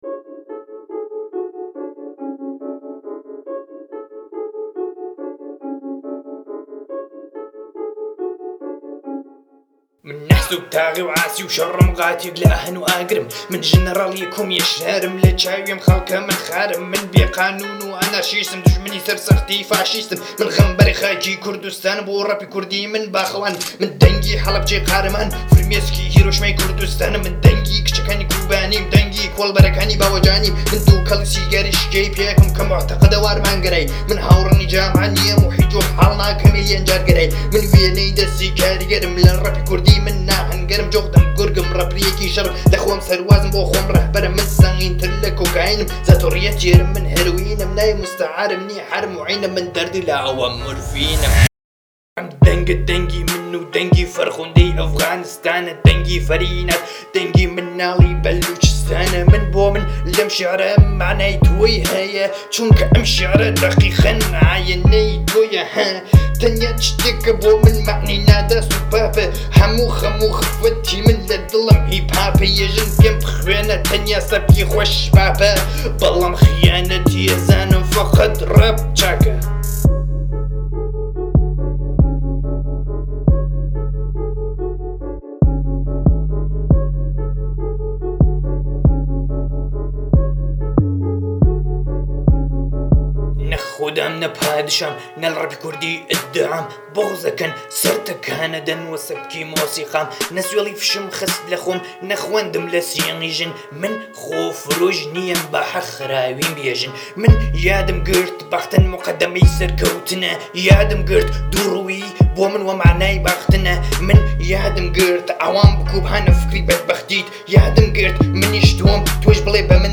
شامل 5 تراک رپ